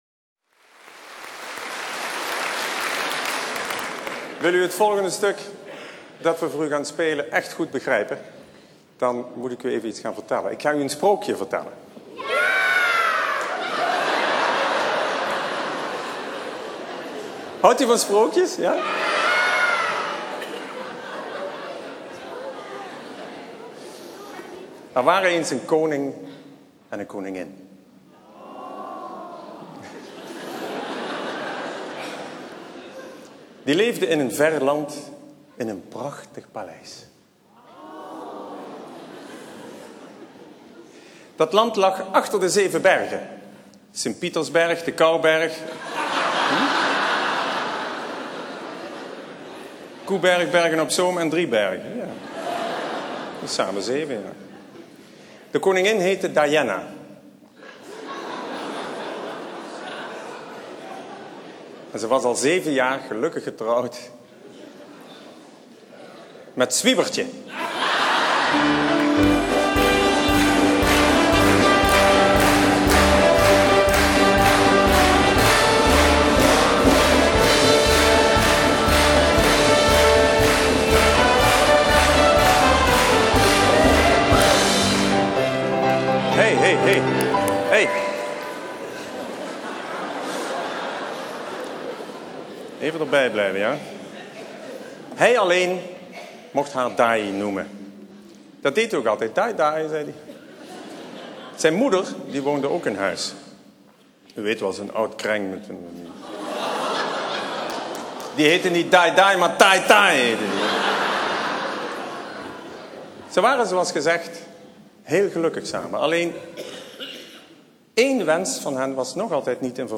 André rit et précise: "Chaque soir, je dois travailler avec ces gens !"
(cris du JSO).